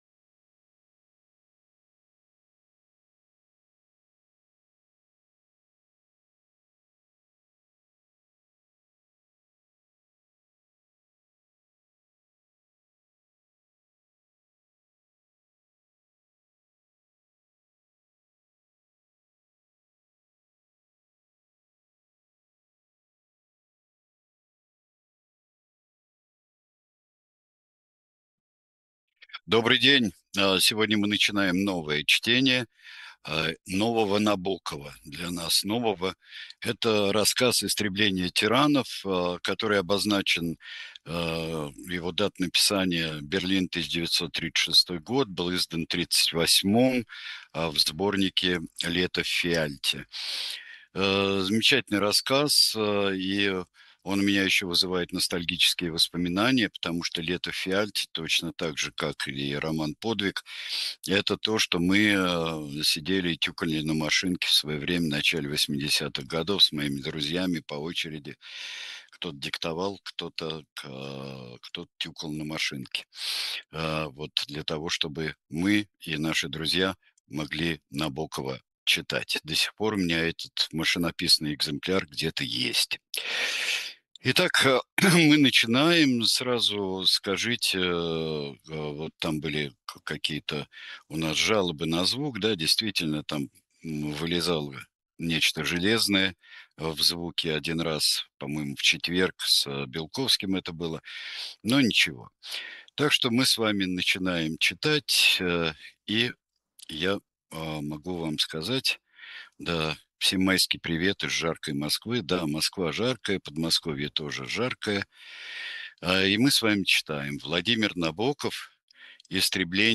Читает произведение Сергей Бунтман